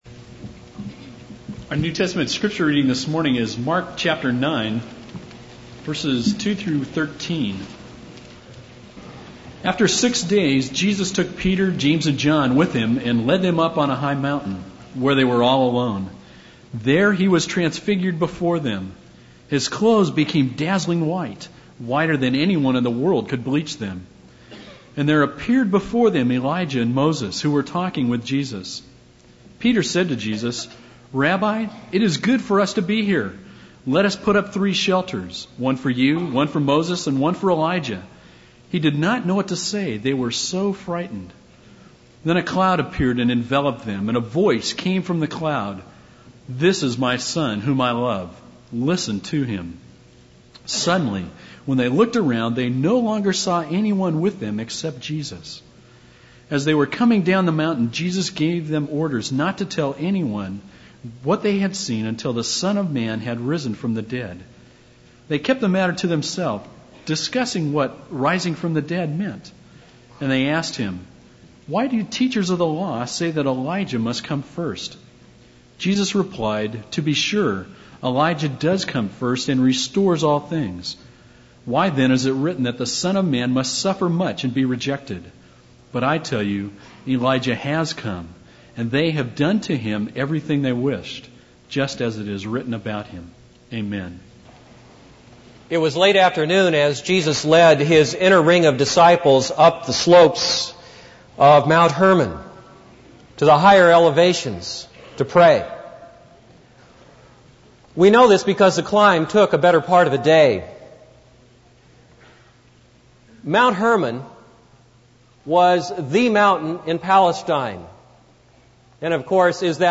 This is a sermon on Mark 9:2-13.